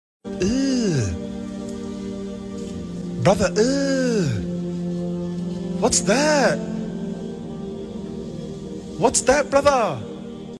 Brother euh Meme Sound sound effects free download